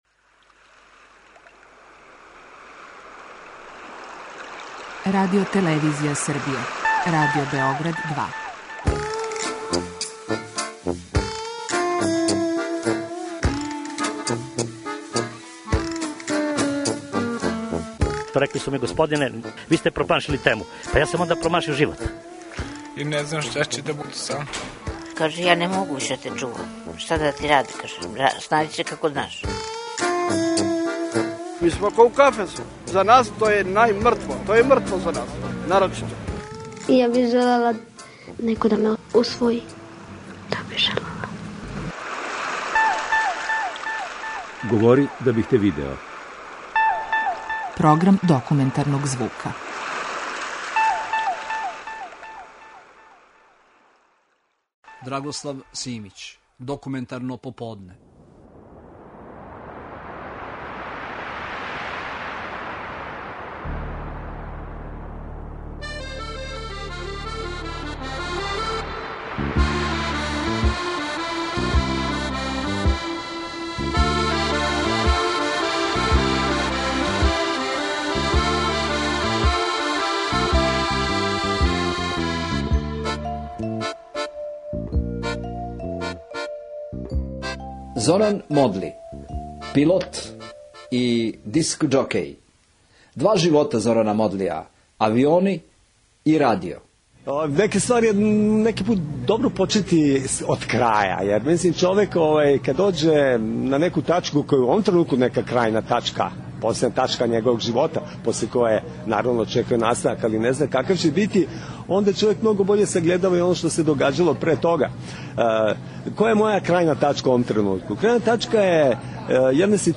Документарни програм
Емисија Два живота Зорана Модлија: пилот и радио-водитељ снимљена је јануара 2013. године на Београдском пристаништу.